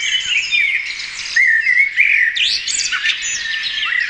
Voegel1.mp3